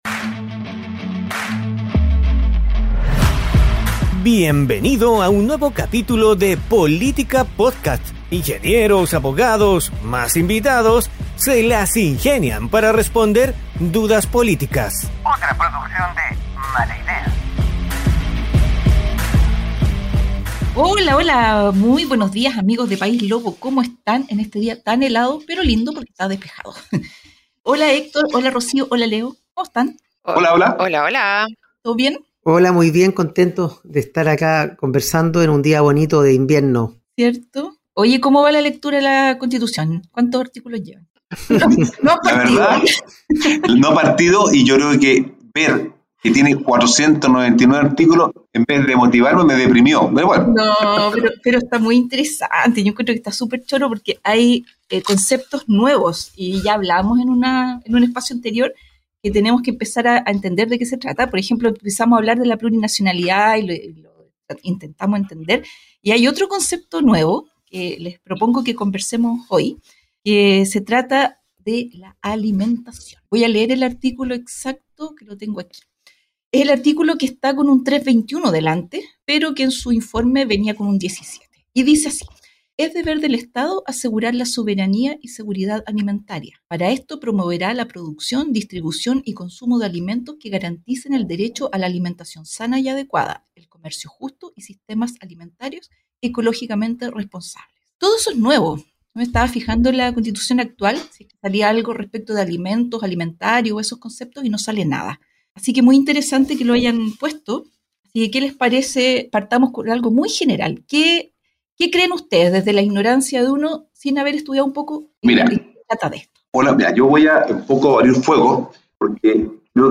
Tres ingenieros se las ingenian para responder dudas políticas